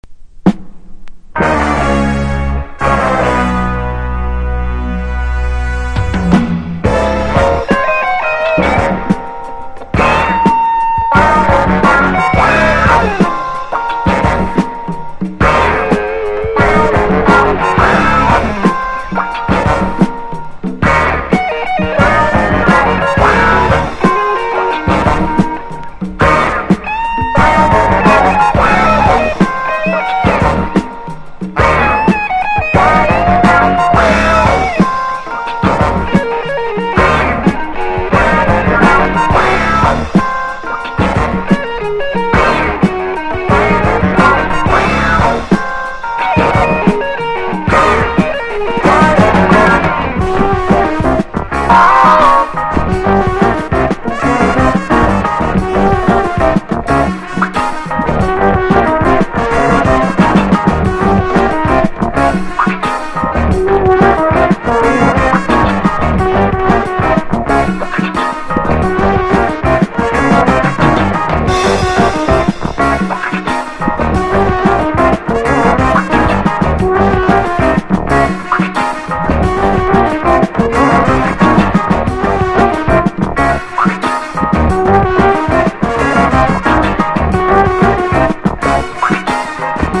全体的にロック色が強い仕上がりになっています。